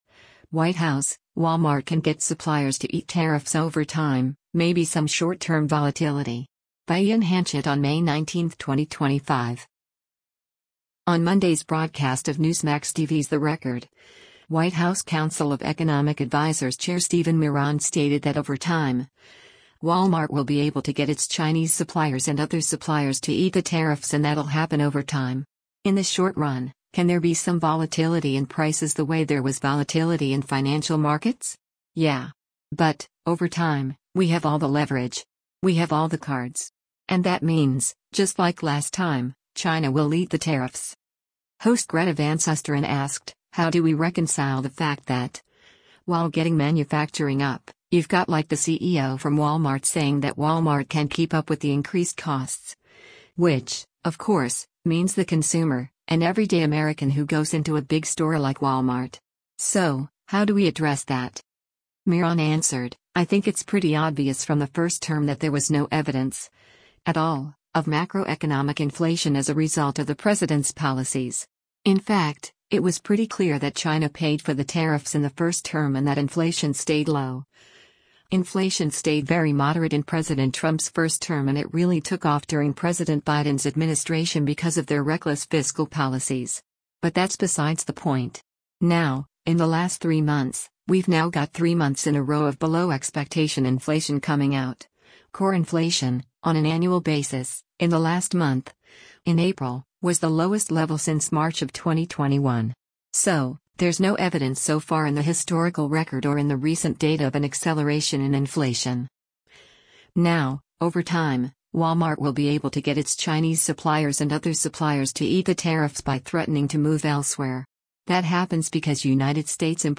On Monday’s broadcast of Newsmax TV’s “The Record,” White House Council of Economic Advisers Chair Stephen Miran stated that “over time, Walmart will be able to get its Chinese suppliers and other suppliers to eat the tariffs” and “that’ll happen over time.